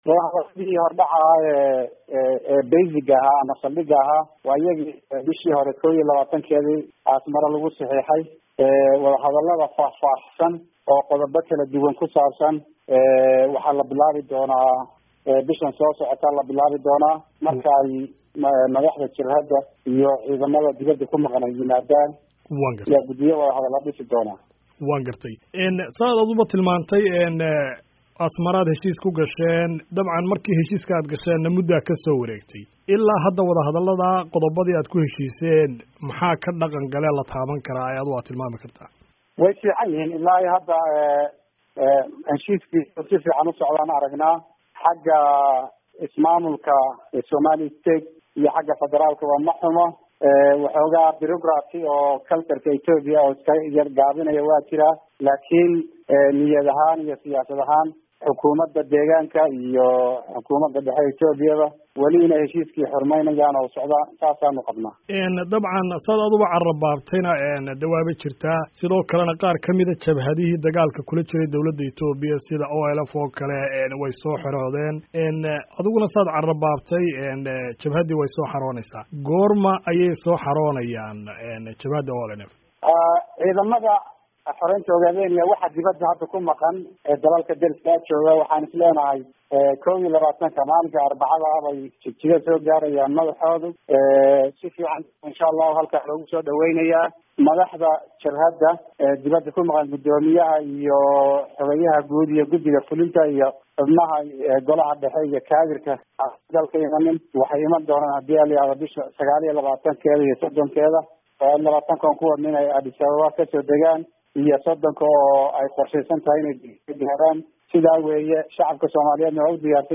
Ururuka ONLF ayaa sheegay in ciidamo ka tirsan jabhadda oo saldhiggoodu ahaa dalka Eritrea inay bilaabayaan inay toddobaadkan dib ugu soo laabtaan Itoobiya. (Halka ka dhageyso wareysi la xiriira)